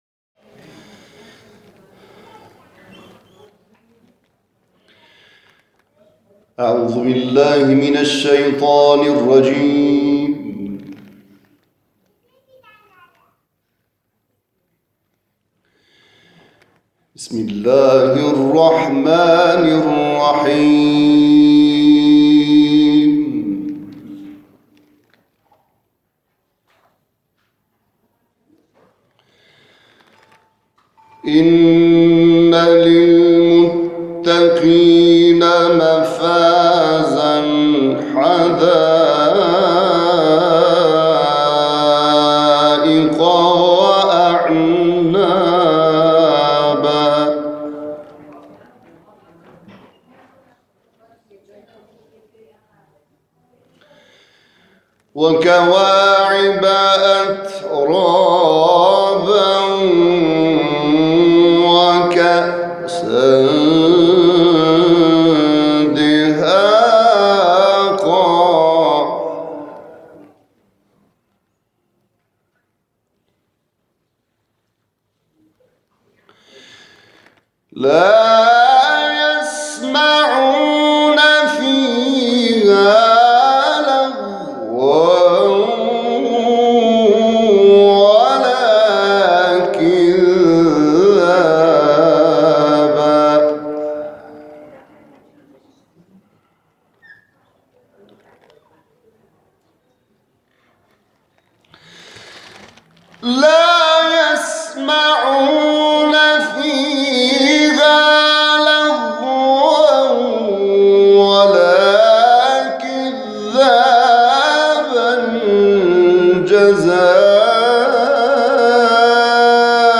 او در محفل بر آستان اشک در فرهنگسرای اندیشه آیات 31 تا آخر سوره مبارکه نبأ را تلاوت کرد.